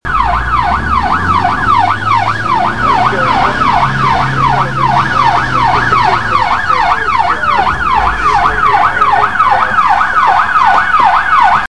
Police Siren